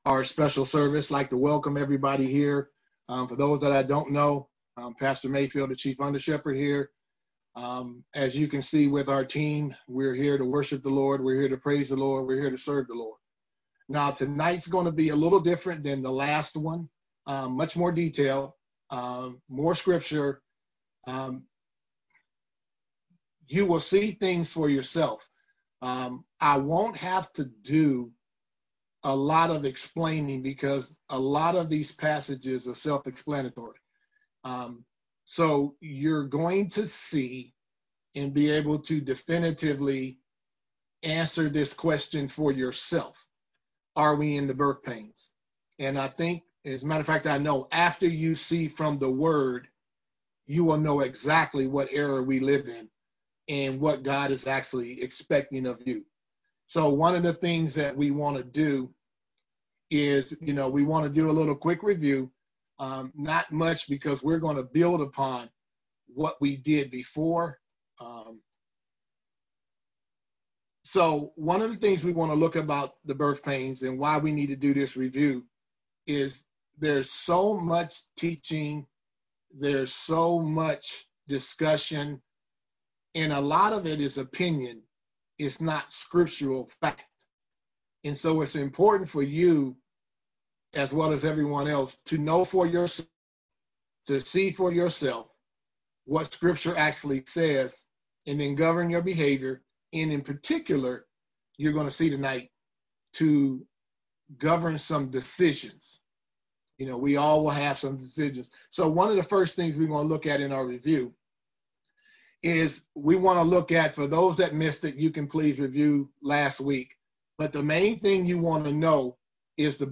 Special Service – Birth Pains Pt 2
Audio Sermon